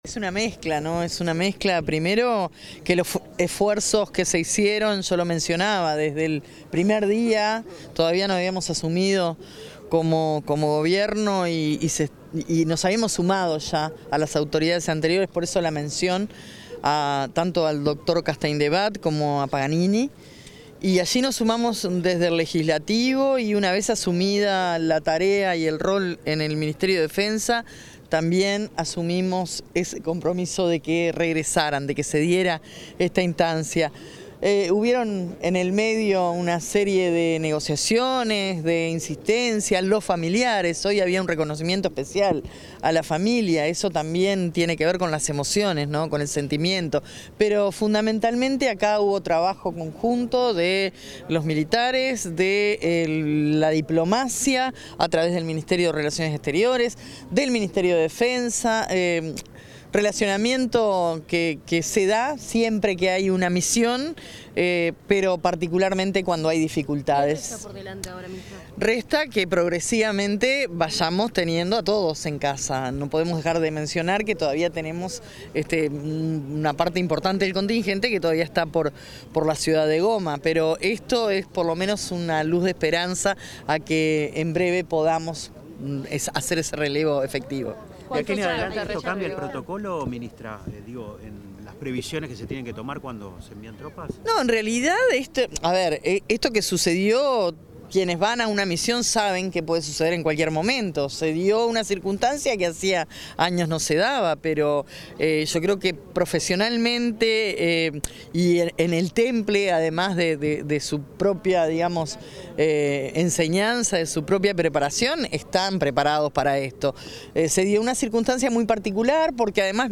Declaraciones de la ministra de Defensa Nacional, Sandra Lazo
Declaraciones de la ministra de Defensa Nacional, Sandra Lazo 04/07/2025 Compartir Facebook X Copiar enlace WhatsApp LinkedIn En la ceremonia de recepción de 200 efectivos que integraron las tropas militares uruguayas en misiones de paz de Naciones Unidas en el Congo, la ministra de Defensa Nacional, Sandra Lazo, dialogó con los medios de prensa.